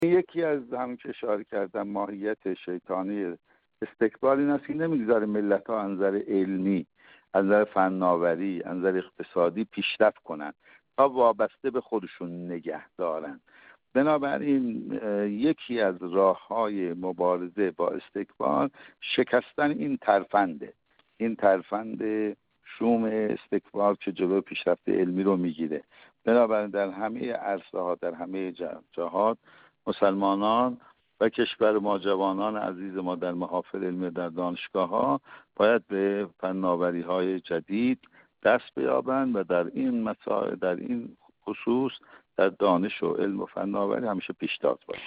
در این راستا حجت‌الاسلام والمسلمین سیدمحمدرضا میرتاج‌الدینی، کارشناس مسائل سیاسی و عضو سابق کمیسیون برنامه و بودجه مجلس شورای اسلامی در گفت‌وگو با ایکنا درباره برخی تحلیل‌ها مبنی بر بر اینکه مشکلات اقتصادی کشور ناشی از مبارزه جمهوری اسلامی ایران با استکبار جهانی است، گفت: استکبار و استعمار در طول تاریخ همیشه سعی بر استضعاف ملت‌ها از جهت اقتصادی، سیاسی و فرهنگی داشته‌اند همانطور که در استعمار کهن نیز قدرت‌های بزرگ با اعمال زور بر ملت‌ها و کشورهای دیگر سلطه پیدا کردند.